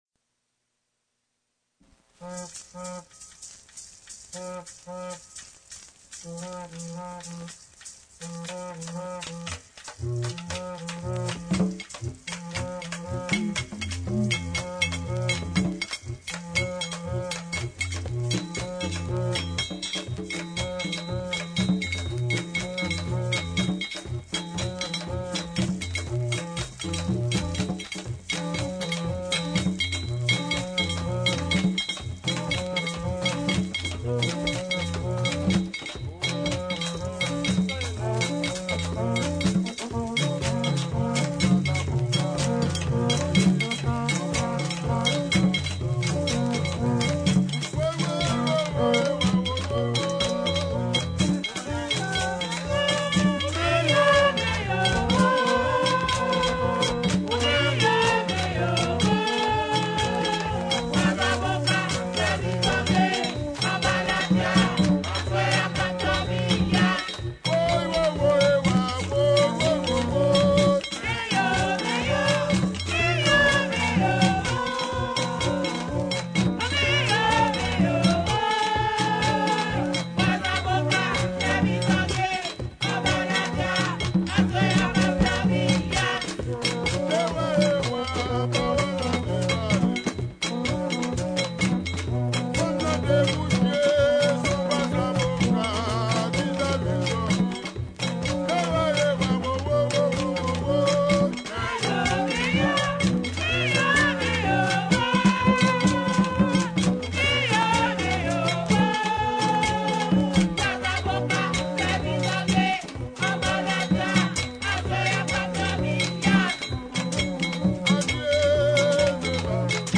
2022 RARA D'HAITI (VACCINE, FLOKLORE HAITIEN) audio closed https